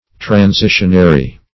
transitionary - definition of transitionary - synonyms, pronunciation, spelling from Free Dictionary
\Tran*si"tion*a*ry\